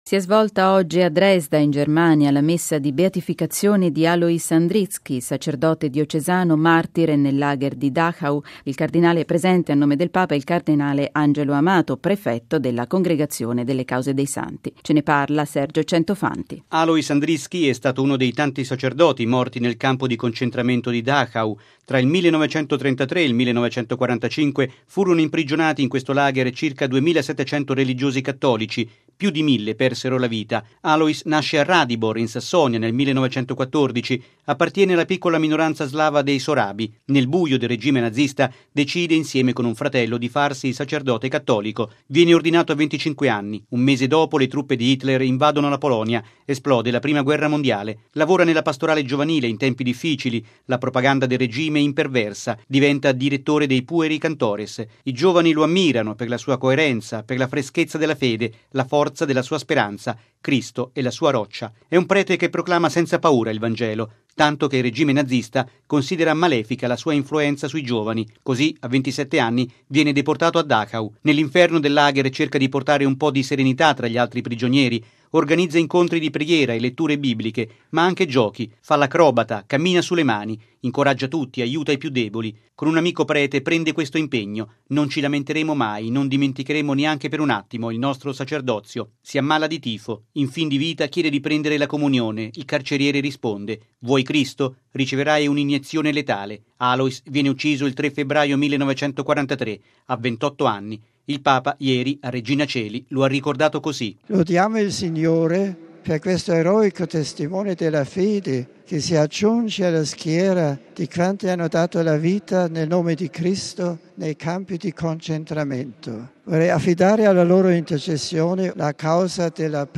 ◊   Si è svolta oggi a Dresda, in Germania, la Messa di Beatificazione di Alois Andritzki, sacerdote diocesano, martire nel lager di Dachau: presente, a nome del Papa, il cardinale Angelo Amato, prefetto della Congregazione delle Cause dei Santi. Il servizio